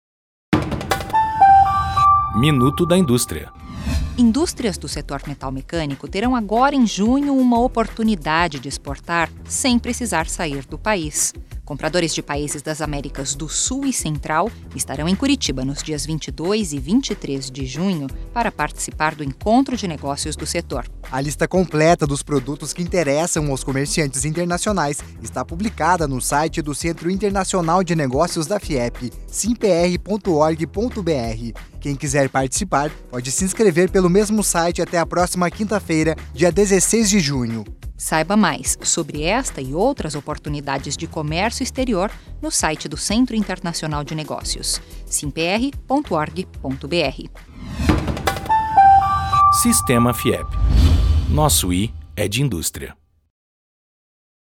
O programa é veiculado em 35 emissoras de rádio espalhadas pelo Paraná. Em apenas um minuto passamos mensagens rápidas sobre os serviços ofertados pelo Sistema Fiep e também sobre nosso posicionamento diante de temas que tenham impacto para a indústria.